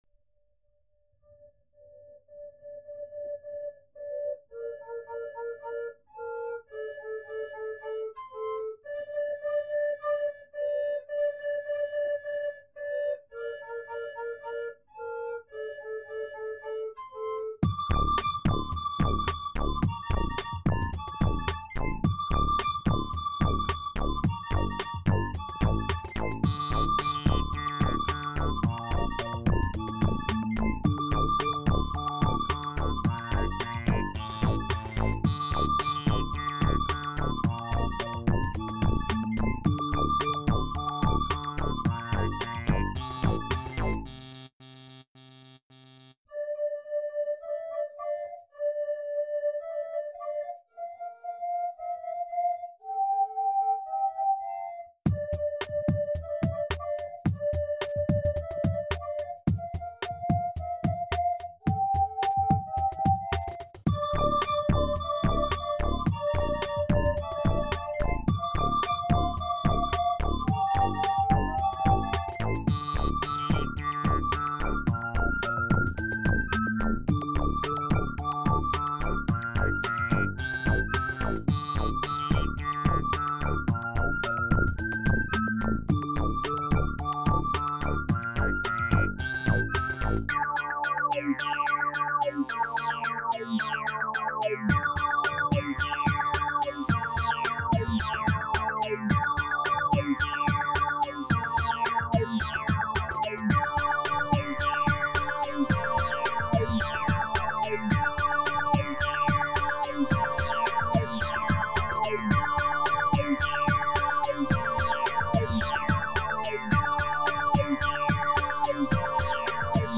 Dieses Lied ist mit einer Altflöte entstanden.